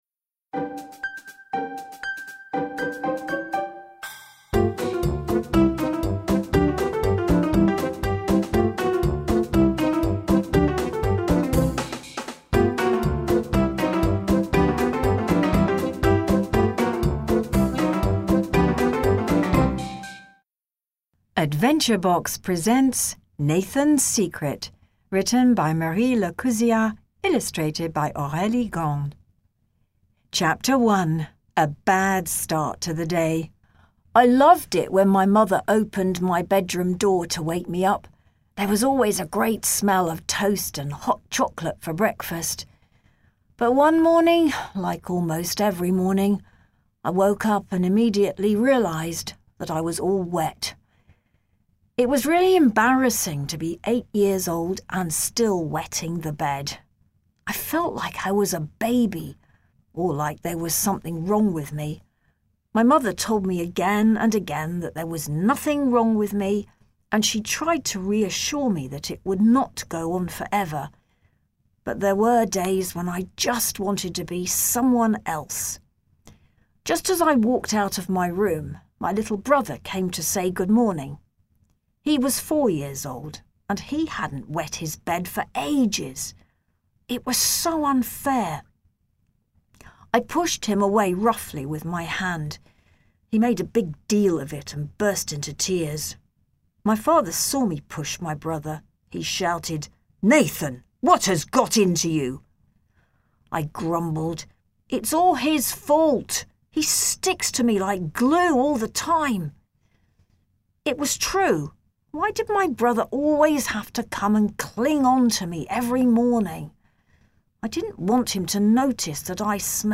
The recording by professional actors brings the stories to life while helping with the comprehension and the pronunciation.